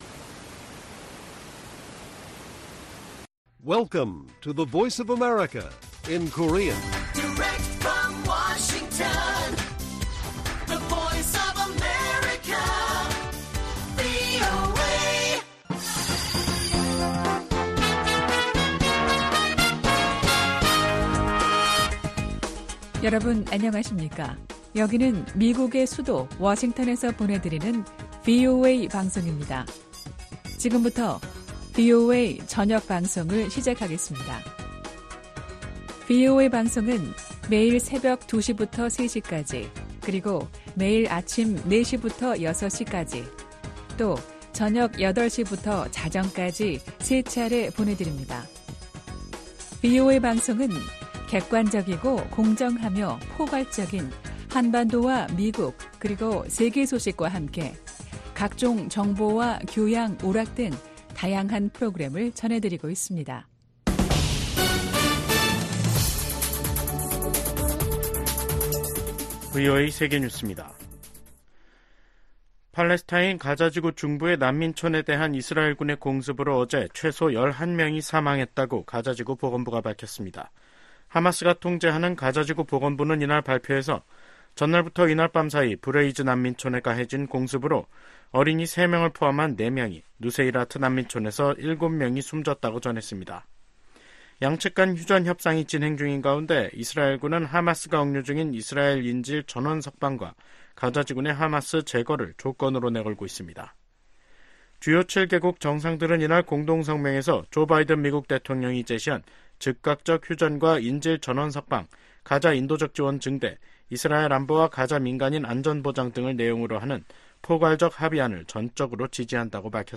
VOA 한국어 간판 뉴스 프로그램 '뉴스 투데이', 2024년 6월 4일 1부 방송입니다. 한국 정부는 9.19 군사합의 효력을 전면 정지시킴으로써 대북 확성기 사용과 함께 군사분계선(MDL)일대 군사훈련 재개가 가능하도록 했습니다. 미국의 북한 전문가들은 군사합의 효력 정지로 한국이 대비 태세를 강화할 수 있게 됐으나, 남북 간 군사적 충돌 위험성 또한 높아졌다고 진단했습니다.